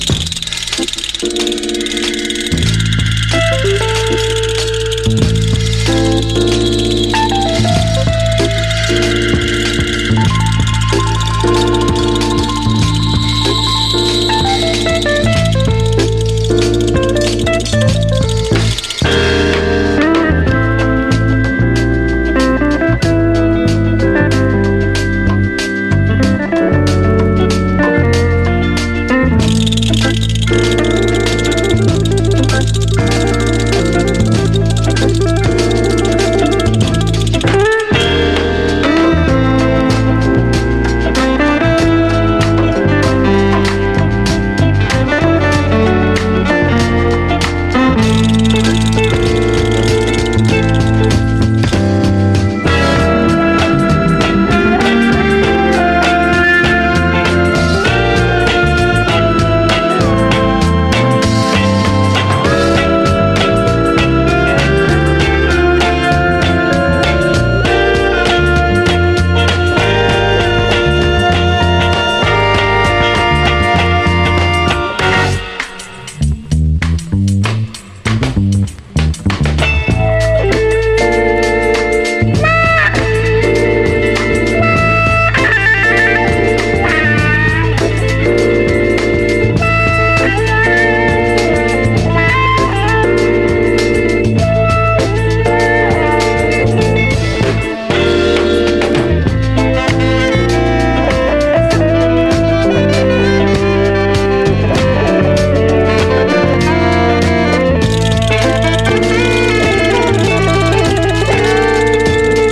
JAZZ FUNK / SOUL JAZZ, JAZZ, REGGAE
ルーツ・レゲエとジャズ・ファンクが奇跡的に融合！